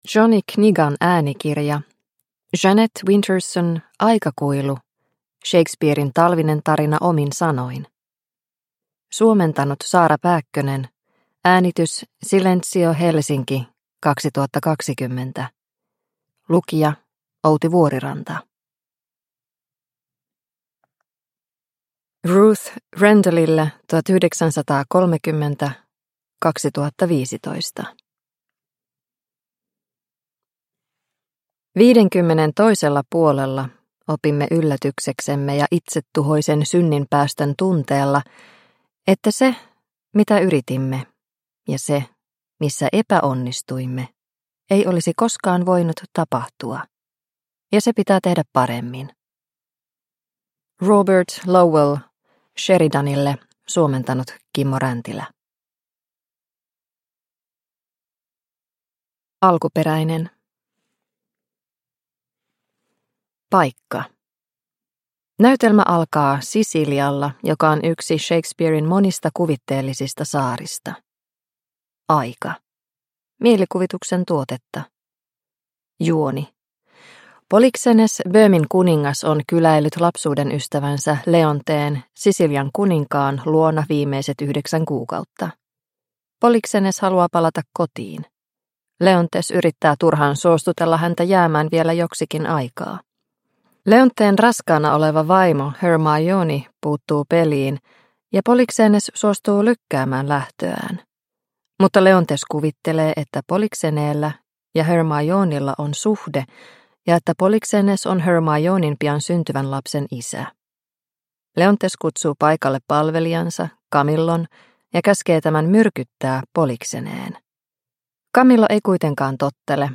Aikakuilu – Ljudbok – Laddas ner